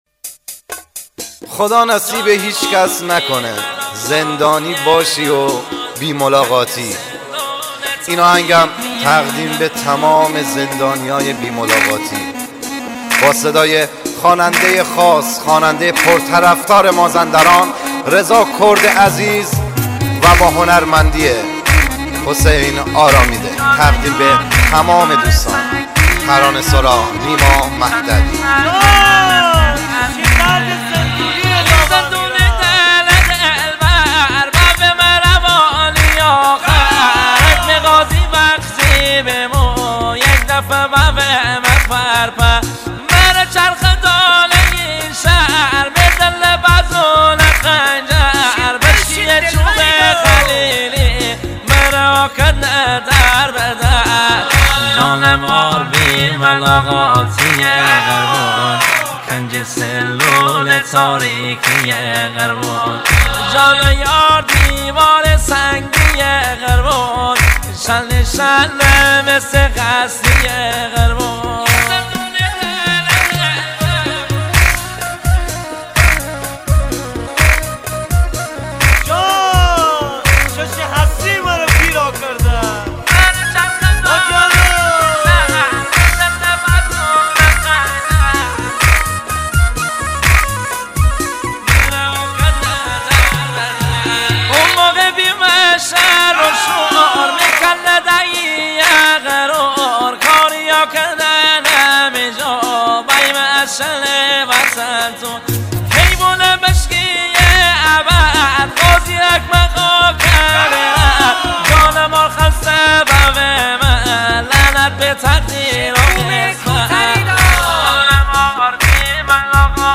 آهنگ مازندرانی غمگین
آهنگ لاتی غمگین مازندرانی
آهنگ لاتی مازندرانی